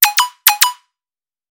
Catégorie: Messages - SMS